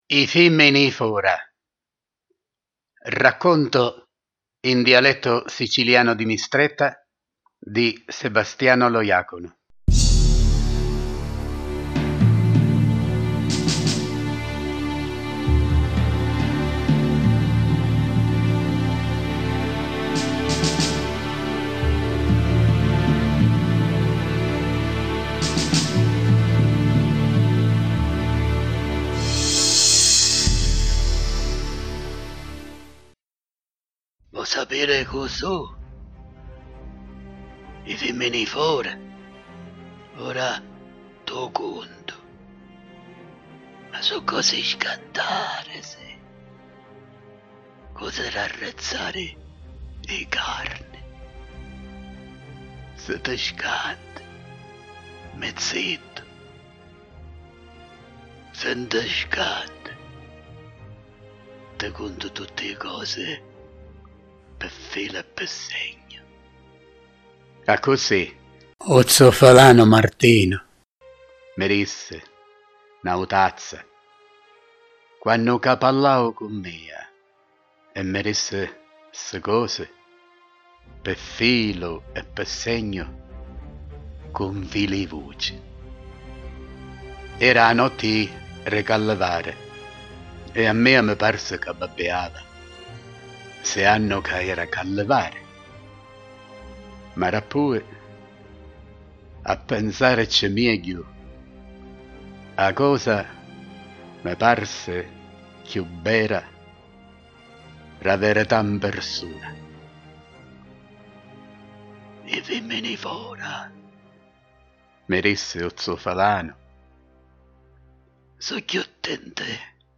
I Fimmini ri fora (integrale con effetti sonori e musica colonna sonora).mp3